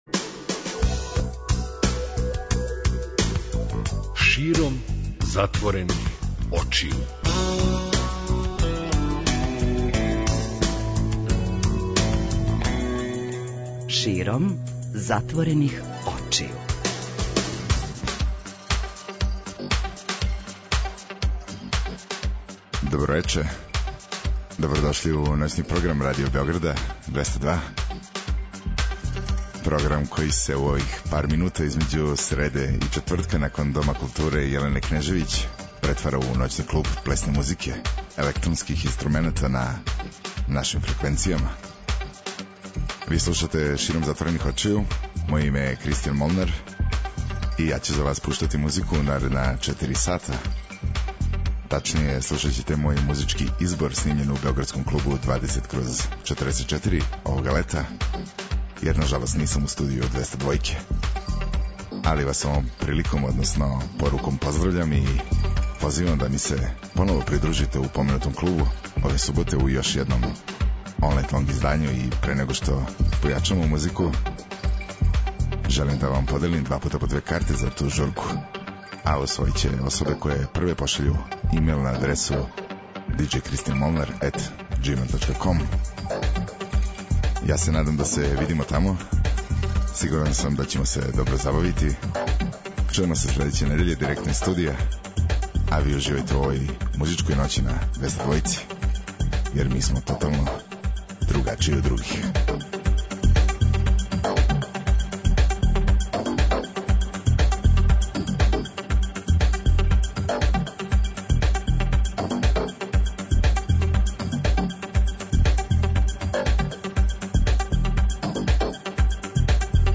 преузми : 54.76 MB Широм затворених очију Autor: Београд 202 Ноћни програм Београда 202 [ детаљније ] Све епизоде серијала Београд 202 Тешке боје Устанак Устанак Устанак Брза трака